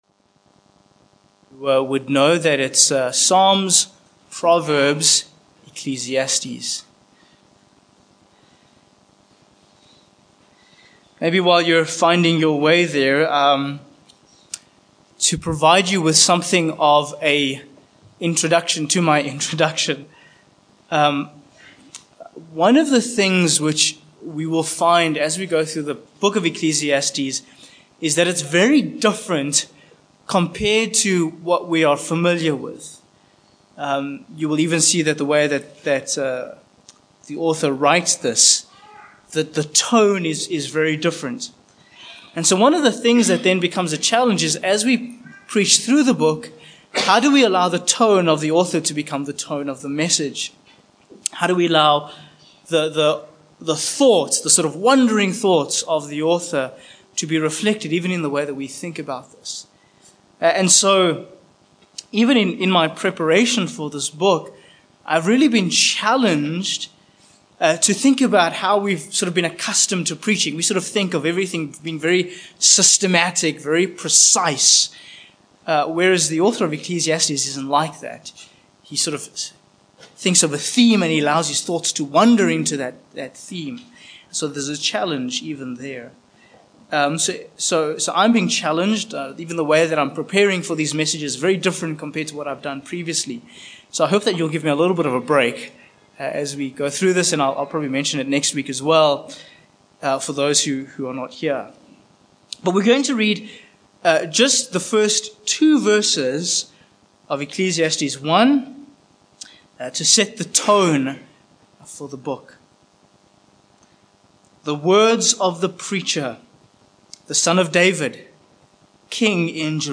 Ecclesiastes 1:1-2 Service Type: Morning Passage